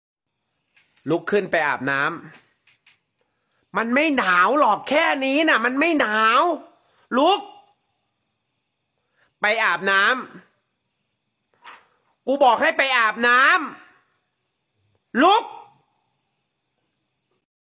หมวดหมู่: เสียงแนวโน้ม